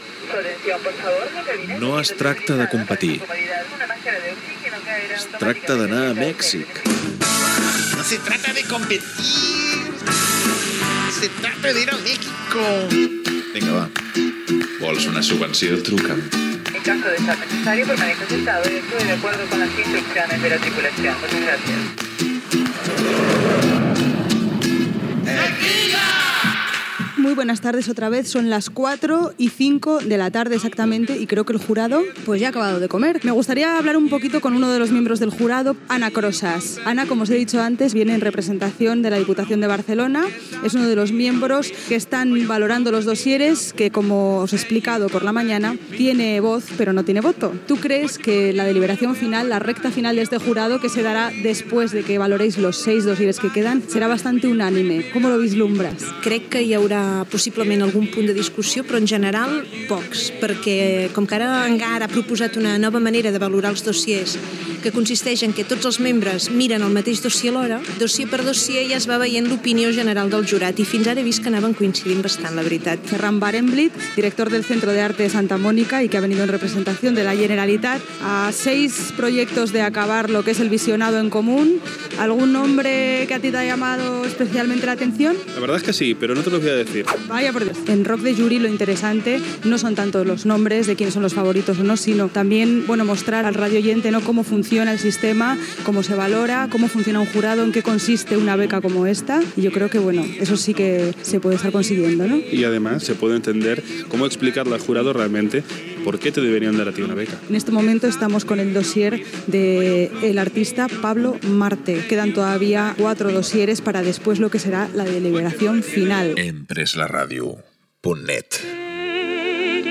Gènere radiofònic Cultura